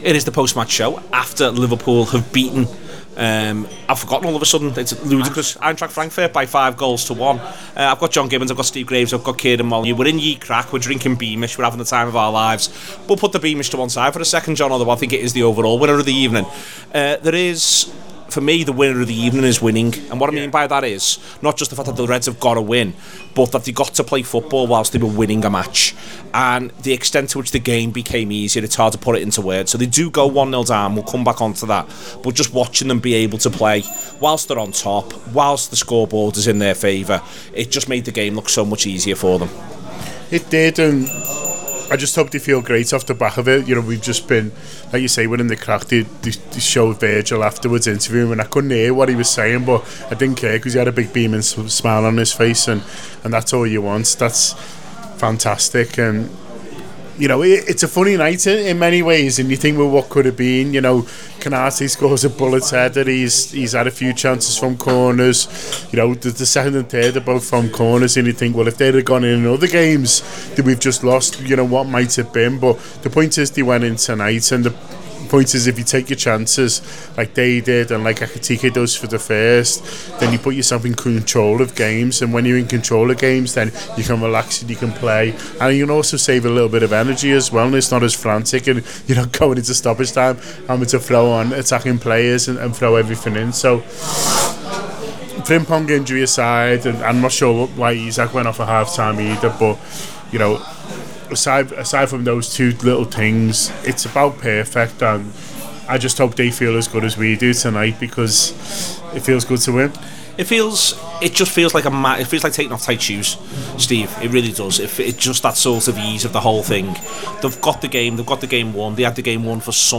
The Anfield Wrap’s post-match reaction podcast after Eintracht Frankfurt 1-5 Liverpool in the Champions League.